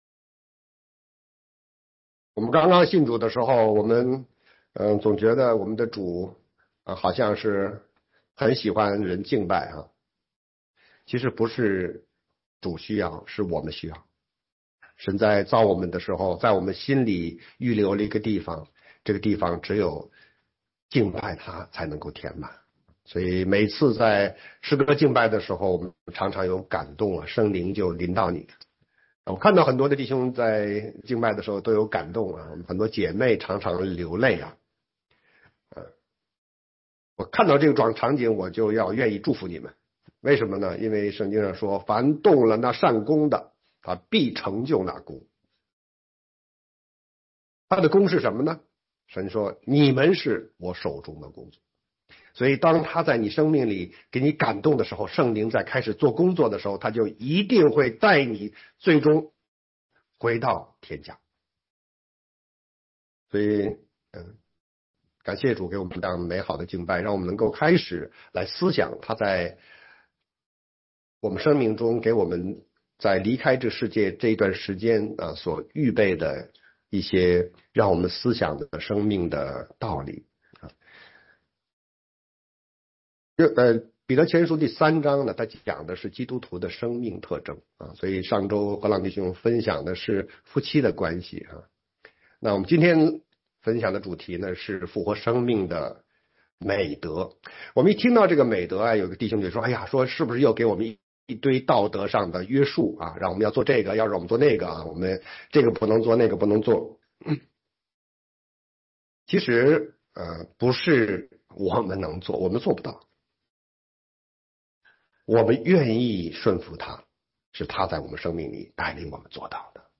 全中文讲道与查经